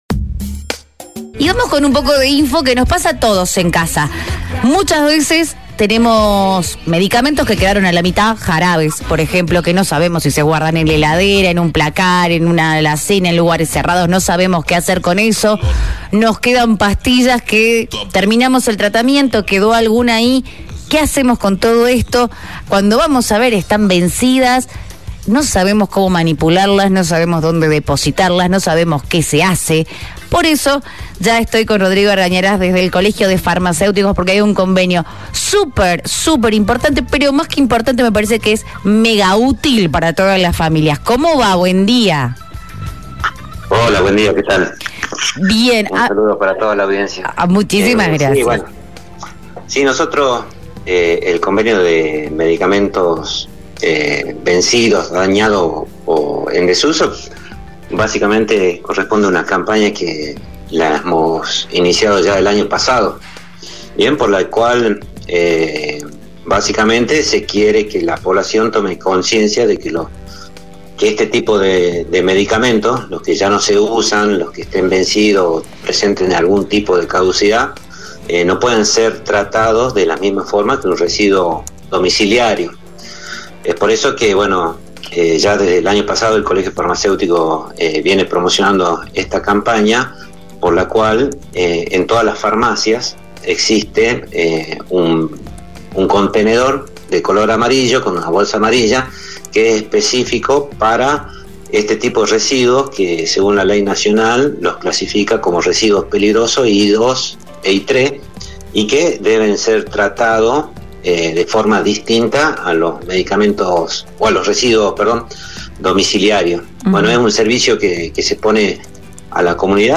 Una charla ágil, informativa que nos llevará a cambios en nuestros hábitos diarios.